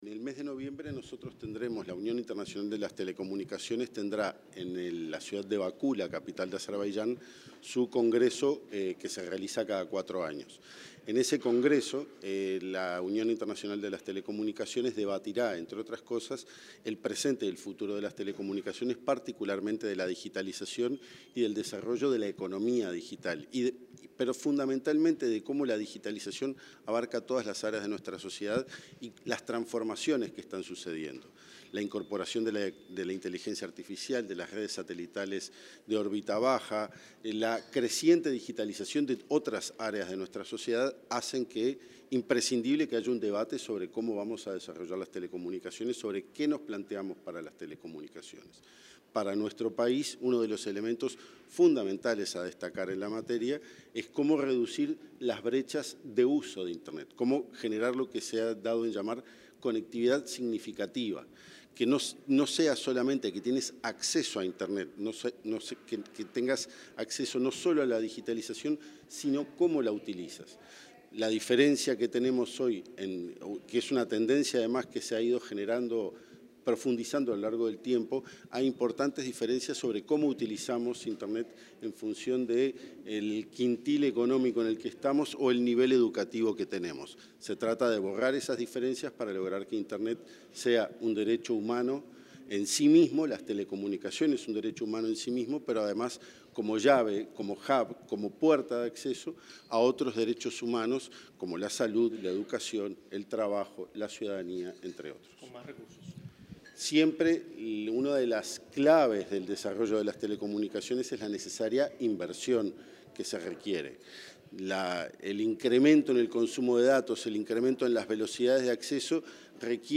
Declaraciones del director de Dinatel, Pablo Siris
Declaraciones del director de Dinatel, Pablo Siris 06/10/2025 Compartir Facebook Twitter Copiar enlace WhatsApp LinkedIn El director nacional de Telecomunicaciones y Servicios de Comunicación Audiovisual del Ministerio de Industria, Pablo Siris, diálogo con los medios de prensa tras la apertura del Coloquio de Política y Economía de las Telecomunicaciones para la Región de las Américas.